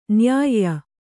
♪ nyāyya